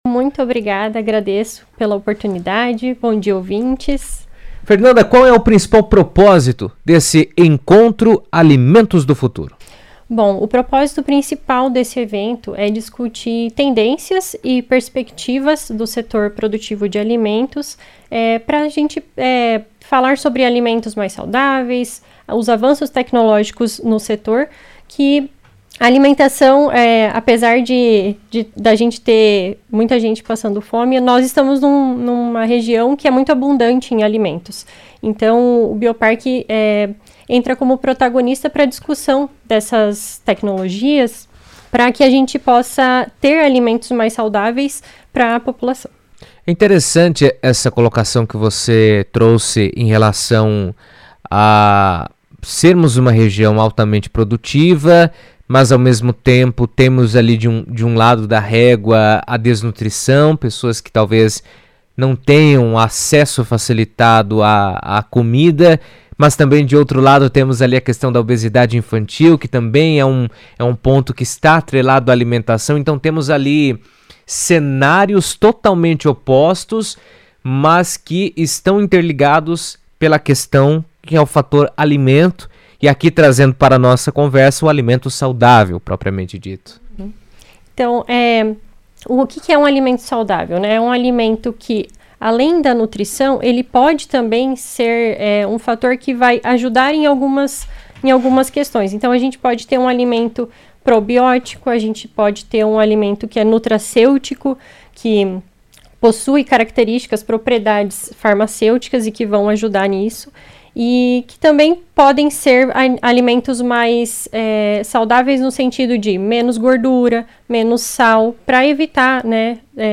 comentará sobre o encontro em entrevista à CBN